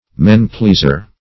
Meaning of men-pleaser. men-pleaser synonyms, pronunciation, spelling and more from Free Dictionary.
men-pleaser.mp3